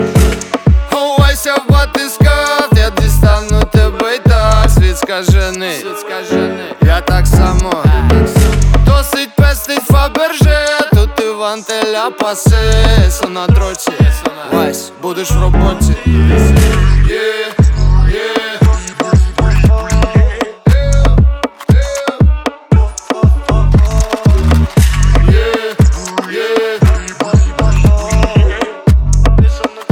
Жанр: Рэп и хип-хоп / Альтернатива
# Alternative Rap